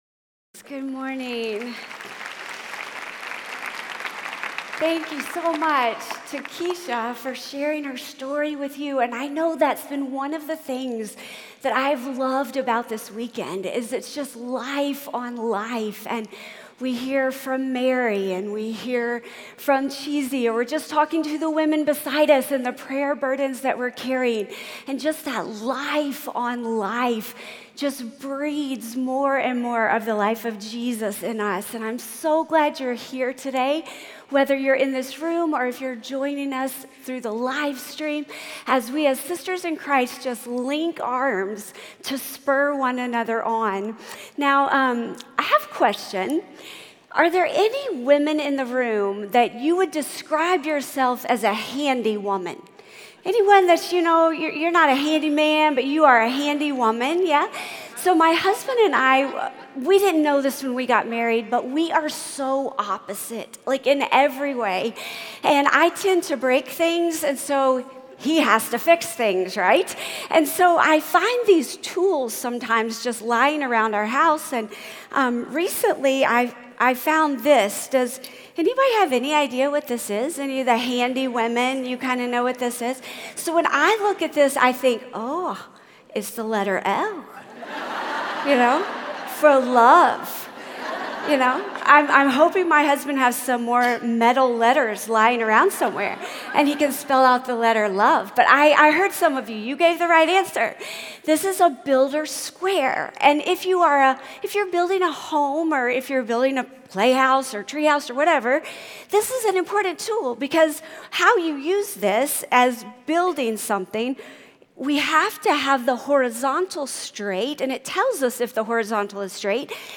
The Two Directions of Forgiveness | Revive '19 | Events | Revive Our Hearts